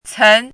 怎么读
cén
cen2.mp3